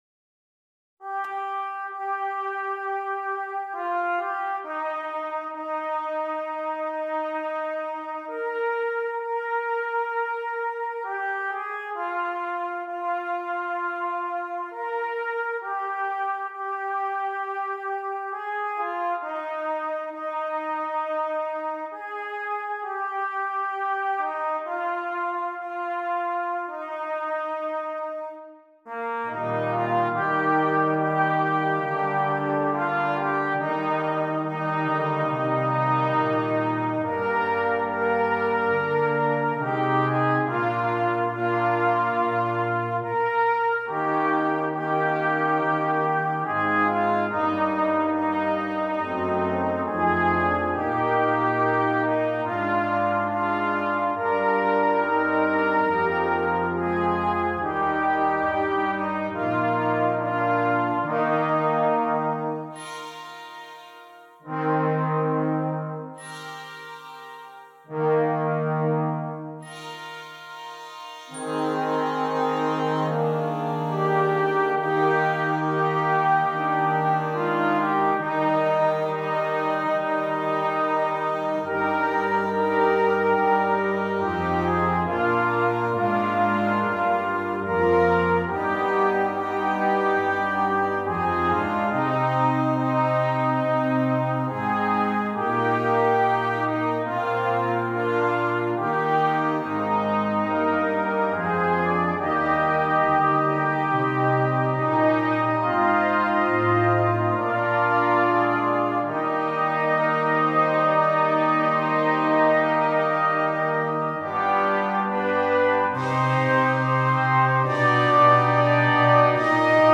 Brass Band
Traditional